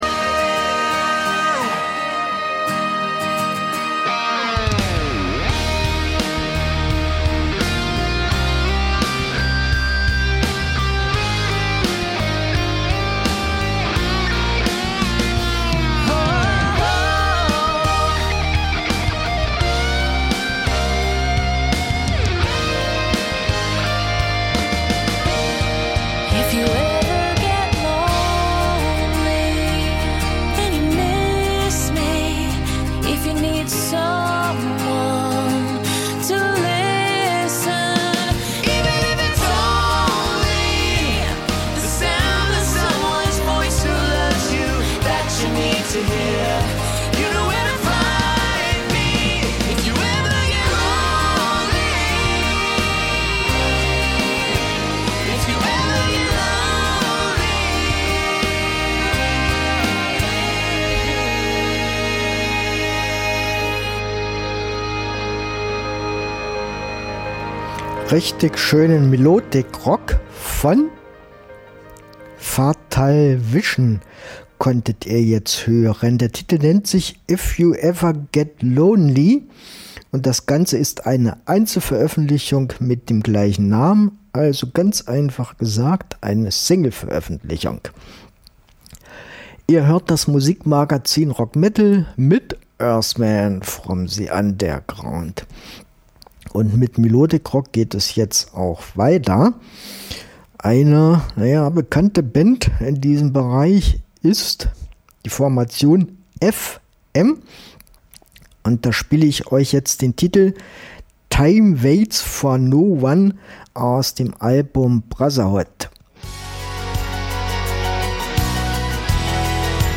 Musik von Rock bis Metal!